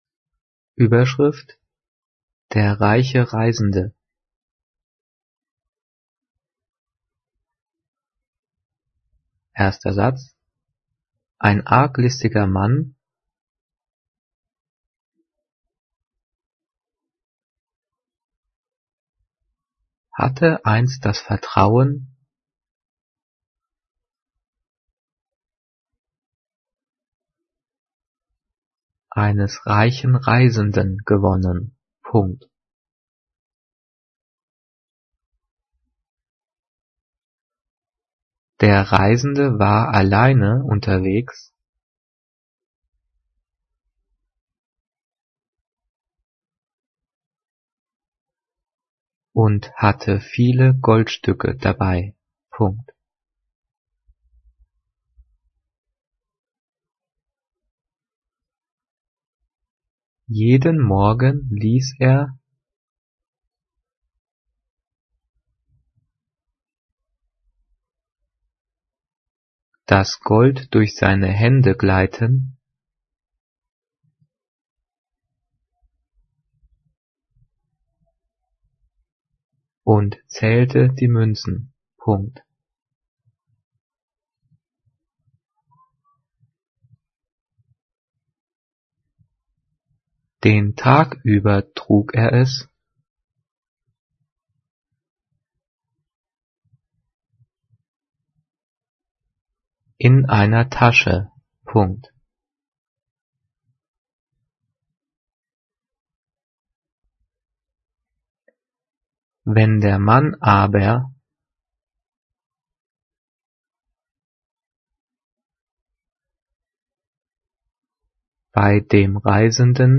Diktiert: